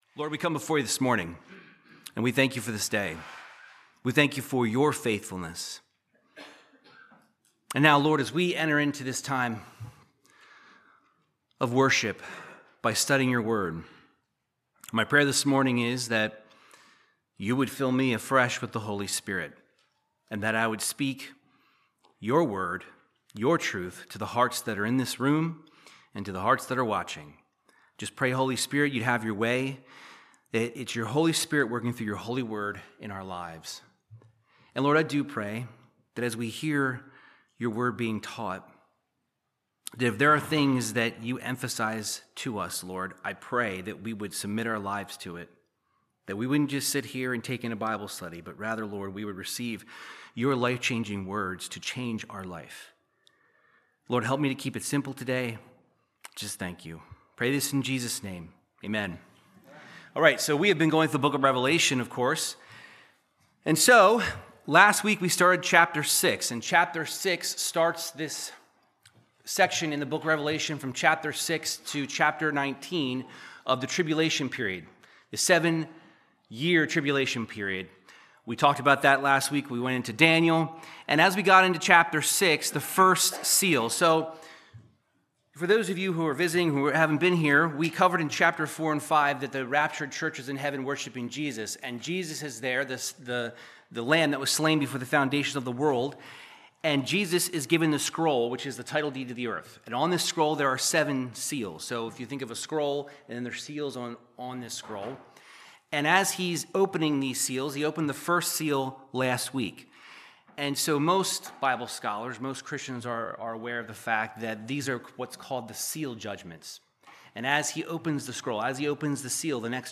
Verse by verse Bible teaching through the book of Revelation 6